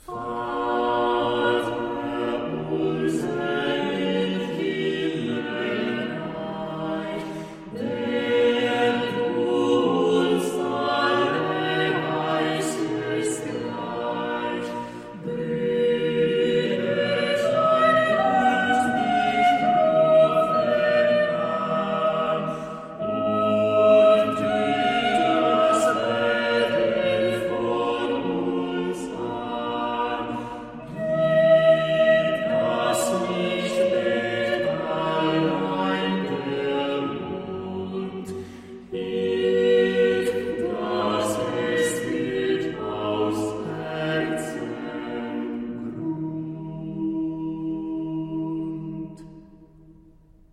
• het meest homofone voorbeeld is voorbeeld 7: alle stemmen bewegen consequent in dezelfde notenwaardes.
De volledige titel van deze verzameling vierstemmig gezette koraalmelodieën luidt: "Kirchengesäng: Psalmen und geistliche Lieder auff die gemeinen Melodeyen mit vier Stimmen simpliciter gesetzet durch Hans Leo Haßler zu Nürnberg"
Het "simpliciter" (=eenvoudig) zal in dit verband vooral te maken hebben met de strikte homofonie van de zettingen.
Uitgevoerd door Il Canto di Orfeo (Youtube)